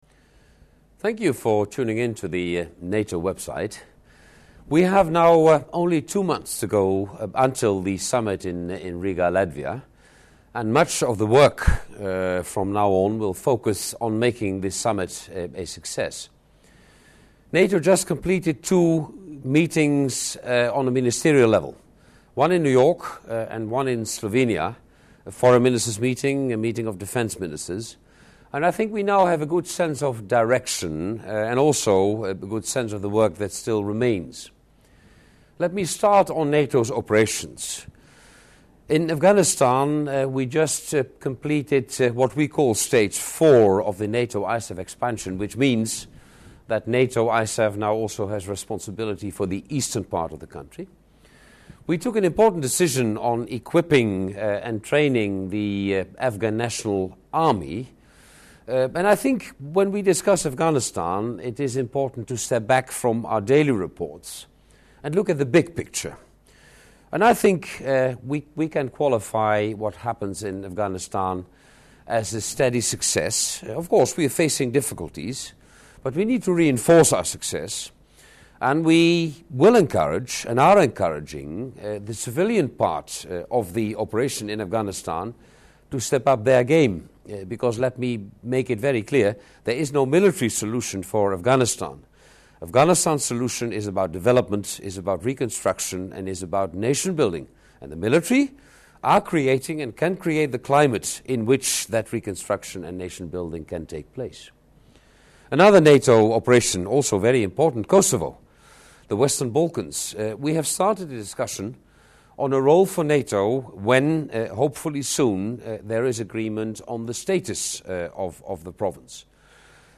Press point with the NATO Secretary General, Mr. Jaap de Hoop Scheffer and the EU High Representative for the Common Foreign and Security Policy, Mr. Javier Solana and EU Special Representative in Bosnia and Herzegovina Dr. Christian Schwarz-Schilling